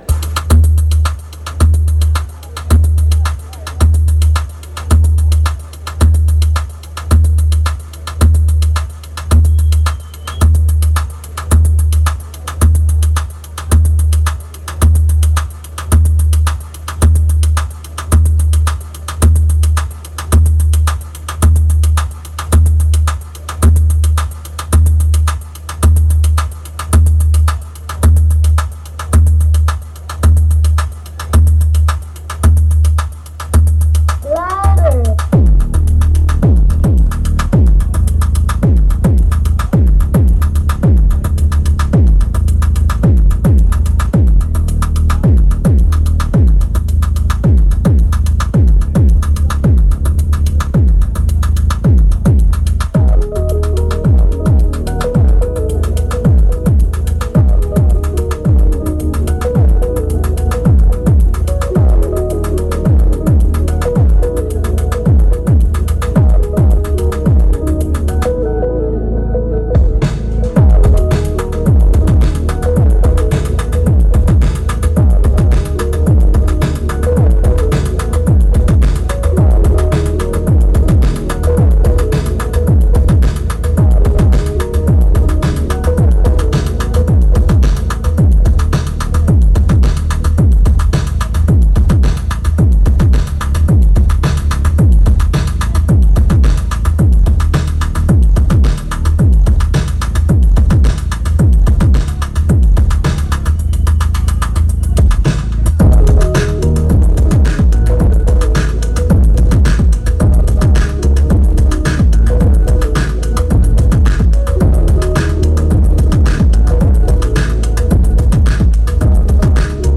event All Tomorrows Parties